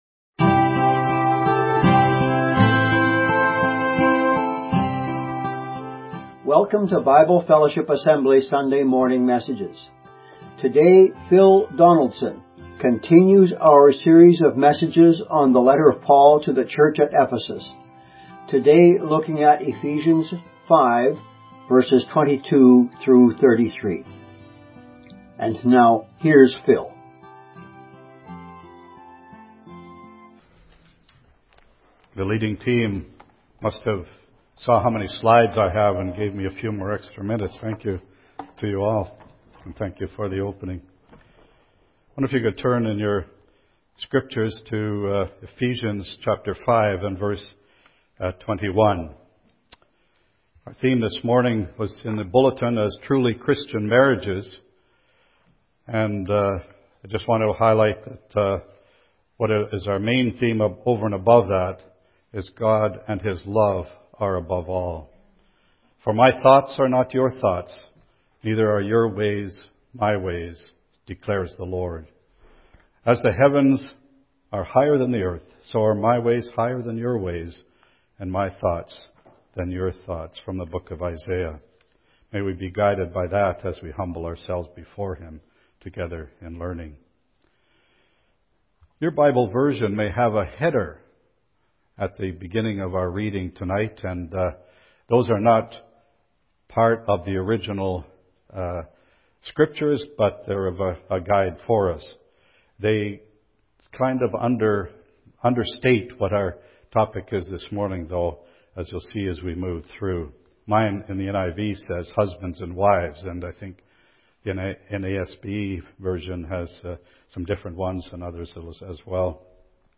1 Richard Gasquet on the Big 3, One-Handed Backhands & His Toughest Matchups (Part 2) | Ep 67 28:16 Play Pause 1d ago 28:16 Play Pause Play later Play later Lists Like Liked 28:16 In part two of our special interview with former World No. 7 Richard Gasquet, the French legend dives deeper into his career with stories, insights, and honesty only a recently retired pro can offer. Richard compares generations, reflects on his toughest matchups, and settles the debate on the greatest one-handed backhand of all time.